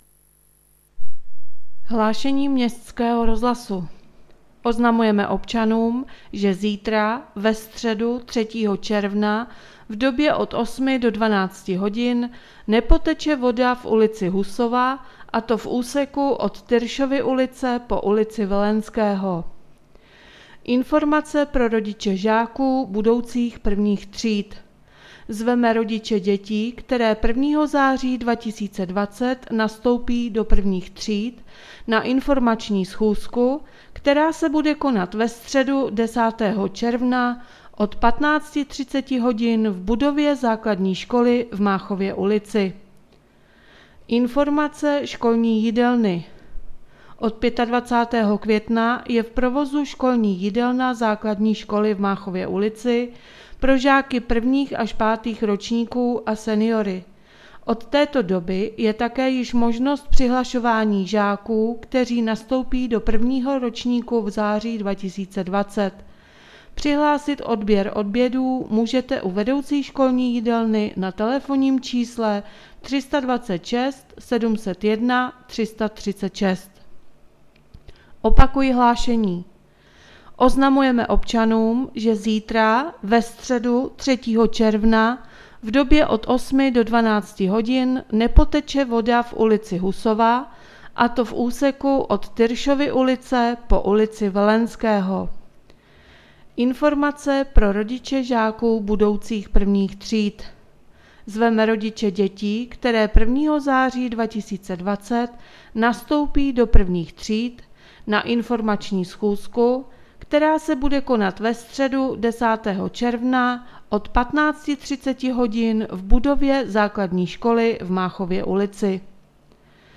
Hlášení městského rozhlasu 2.6.2020